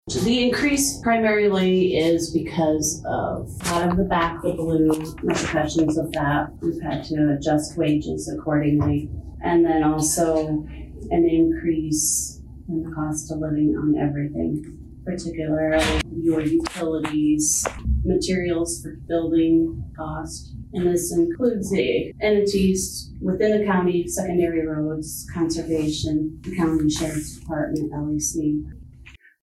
Humboldt County Auditor Trish Erickson says a few factors led to the increases in the tax levy.